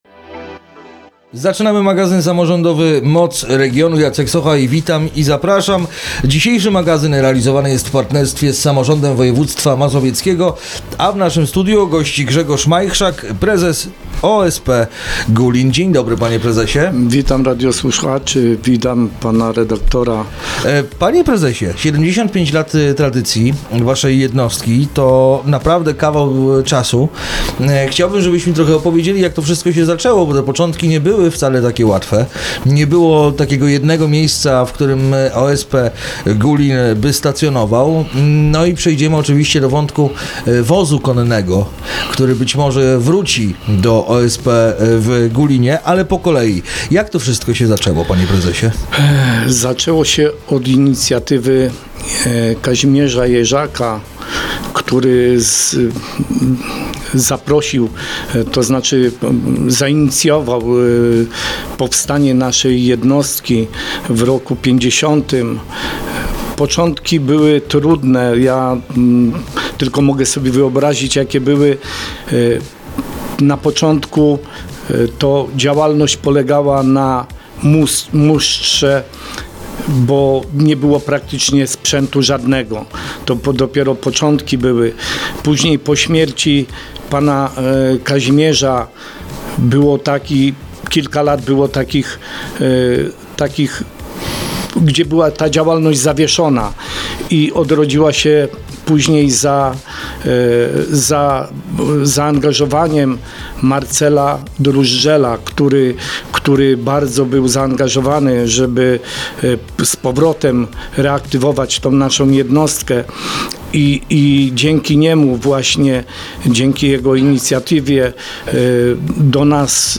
Rozmowa jest dostępna także na facebookowym profilu Radia Radom: